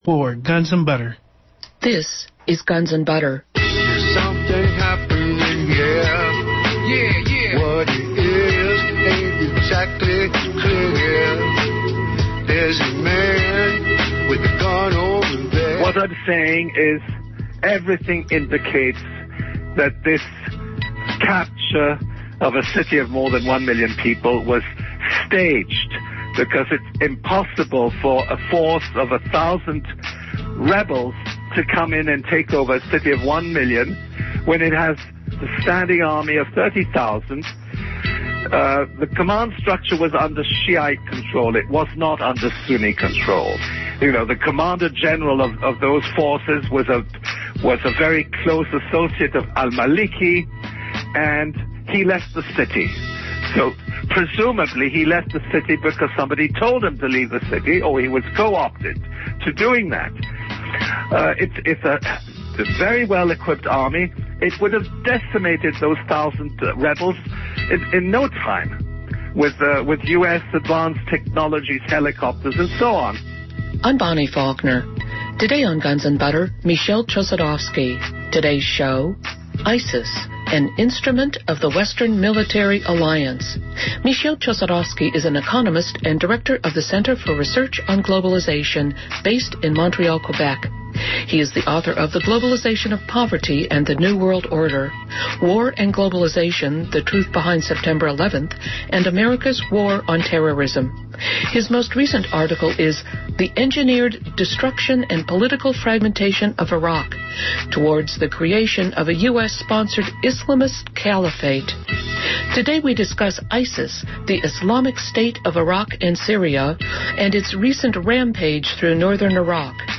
Highlights of the interview include: